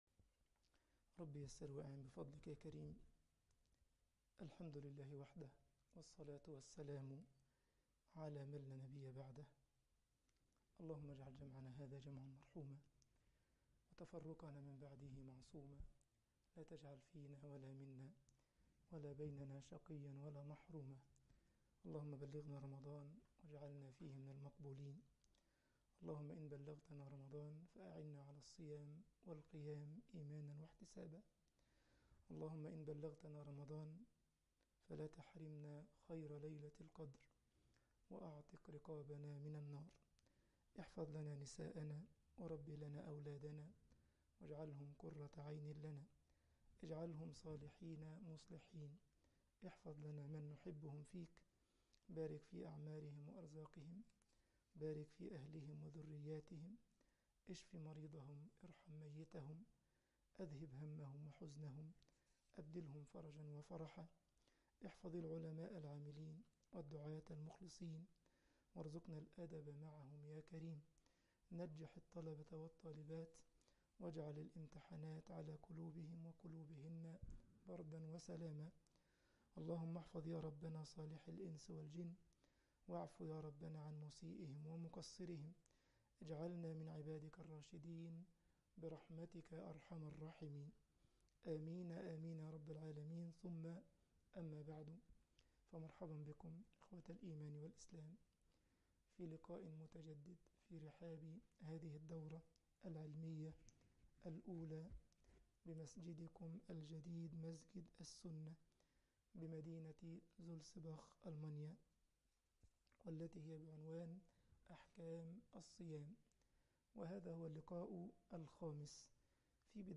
الدورة العلمية رقم 1 أحكام الصيام المحاضرة رقم 5